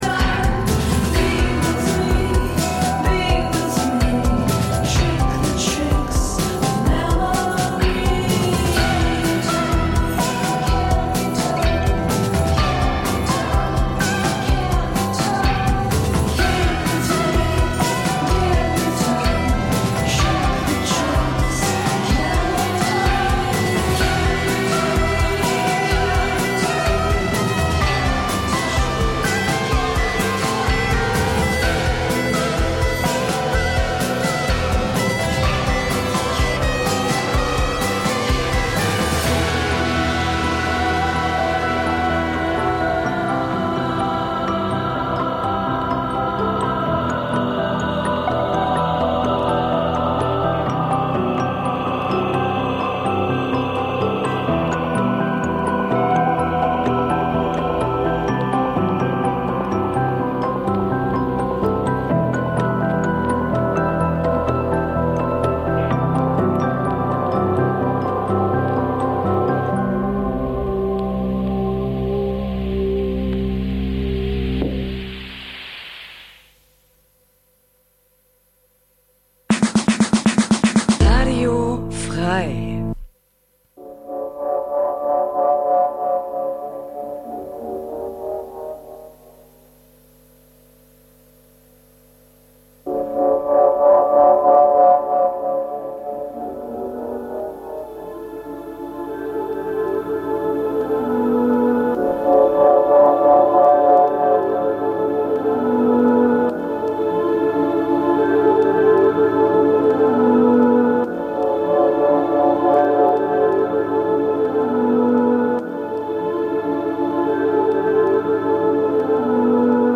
Trotzdem lassen wir es uns nicht nehmen immer wieder sonntags ein paar ganz besondere Perlen unserer Schallplattensammlung einer m�den aber durchaus interessierten H�rer-schaft zu pr�sentieren. Hierbei handelt es sich ausschlie�lich um leicht bek�mmliche Musikst�cke aus dem Be-reich Jazz, Soul, Funk, Soundtracks, Beat, French Pop u.s.w. Ganz nebenbei geben wir dem H�rer Informationen zu den gespielten Musikst�cken und vermitteln ihnen wertvolle Tips zu Bew�ltigung des
Easy Listening Dein Browser kann kein HTML5-Audio.